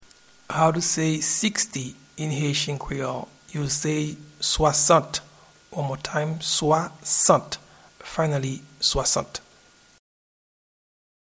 Pronunciation and Transcript:
Sixty-in-Haitian-Creole-Swasant.mp3